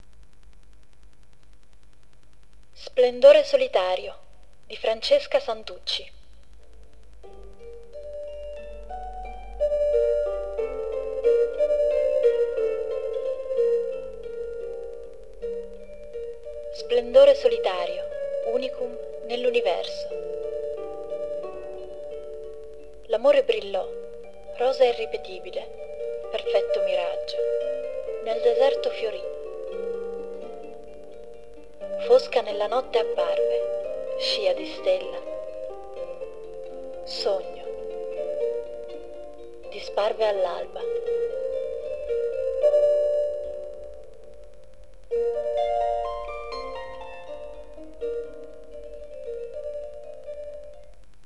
file vocale (dicitore di " Penna d'Autore "